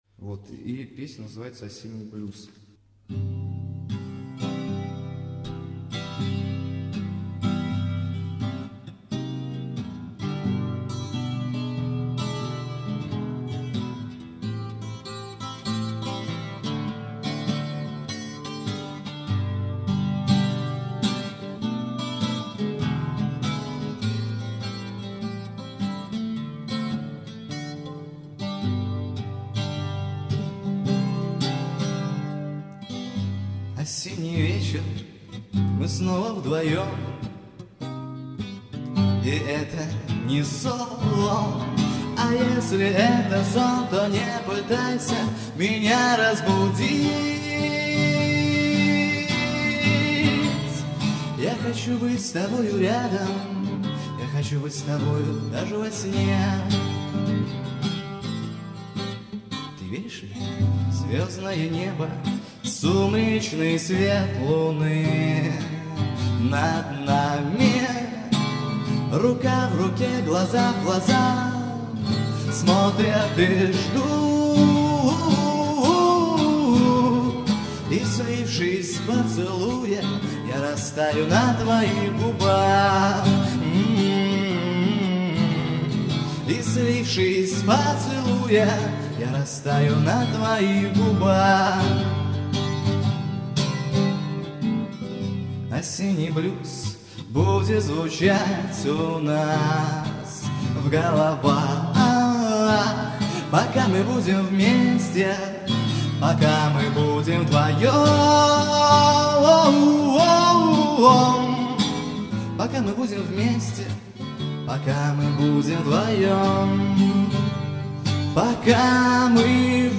Концерт в ДК им. Дзержинского.